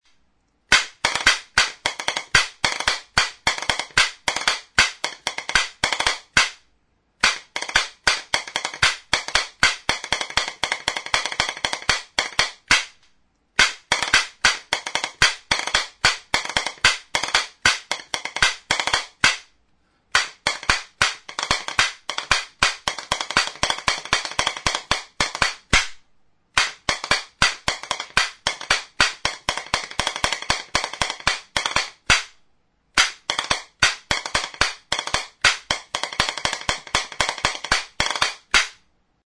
Idiófonos -> Golpeados -> Indirectamente
FANDANGOA.
Oiartzun, 1999.